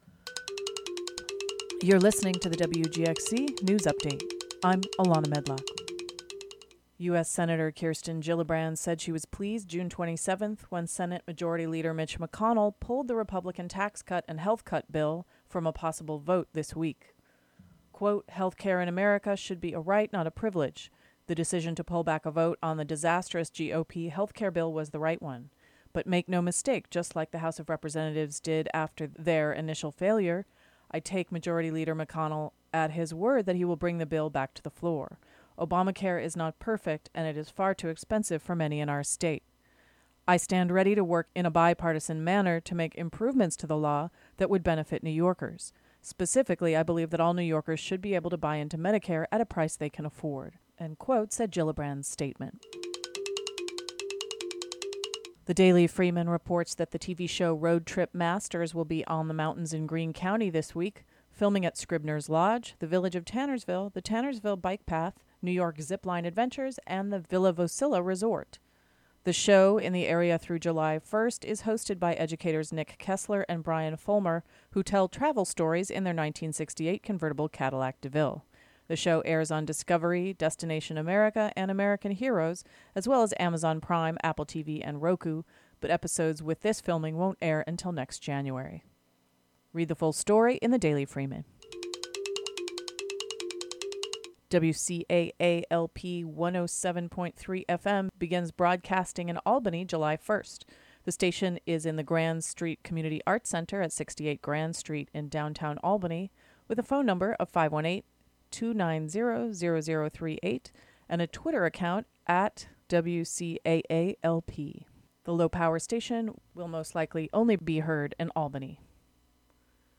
WGXC Local News Audio Link